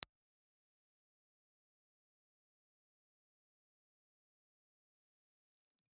Ribbon
Impulse Response file of the LEM ribbon microphone.
LEM_Ribbon_IR.wav